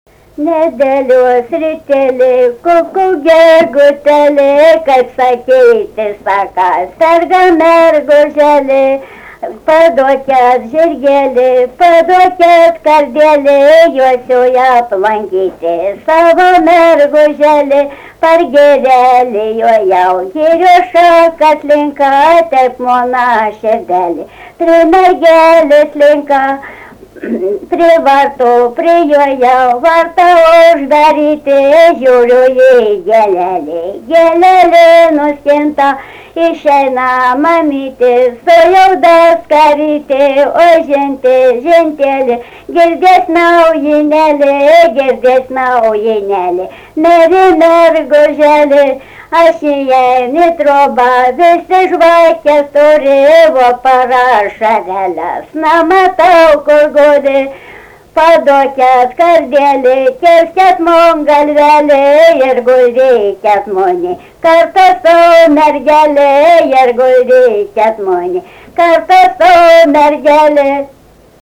Biržuvėnai
vokalinis